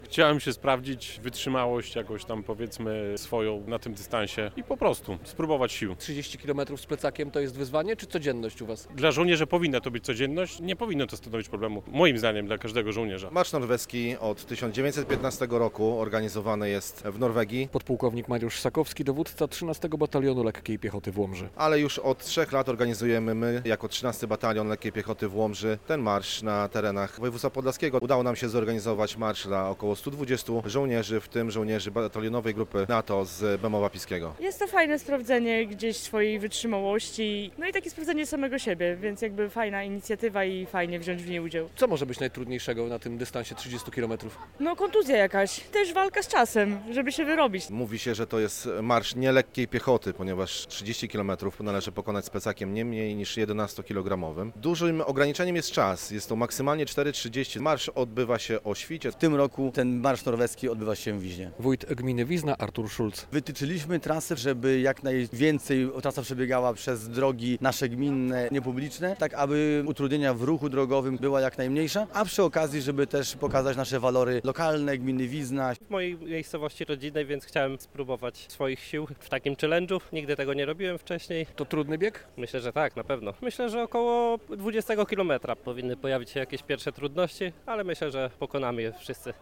30 kilometrów z plecakiem. Norwegian Foot March w Podlaskiem - relacja